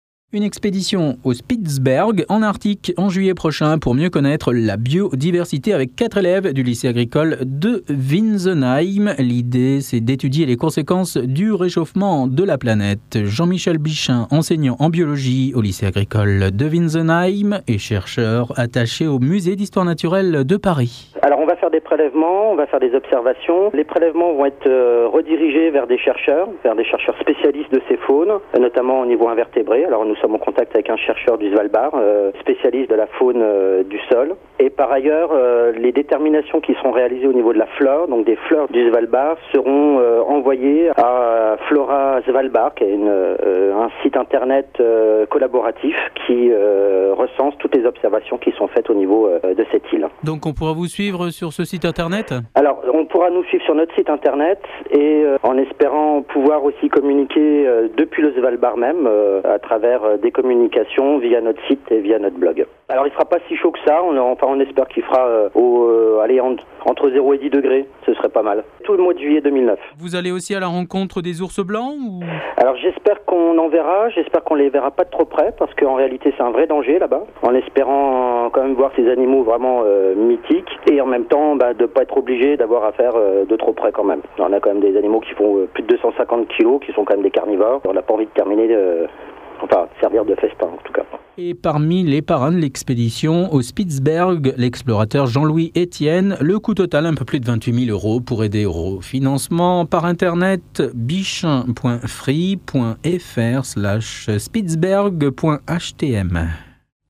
Flash info diffusé sur Radio Azur le 10 fév. 2009